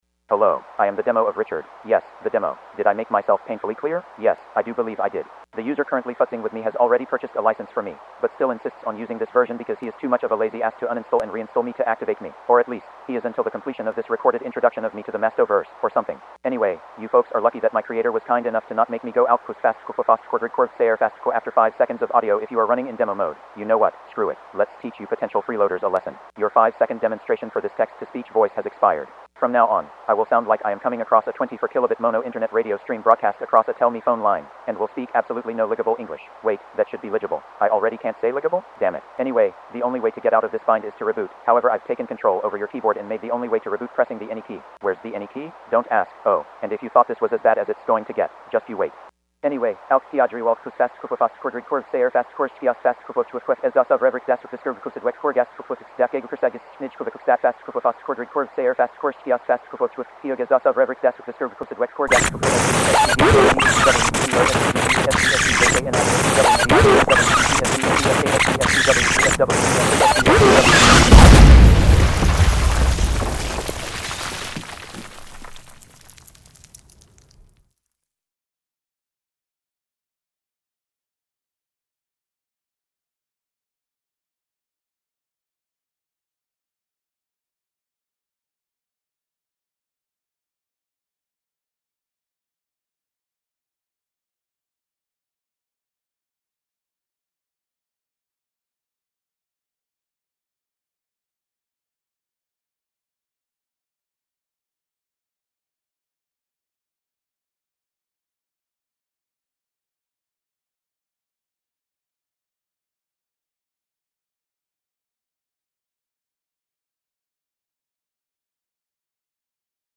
Today saw the introduction of something ntruly worthy of celebration: a modern, 64bit, truly responsive formant-neural sapi voice for Windows. Today also saw the introduction of a recording that is based around the fact that that demo of the voice does actually downsample after a minute of audio.